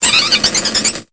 Cri de Galvaran dans Pokémon Épée et Bouclier.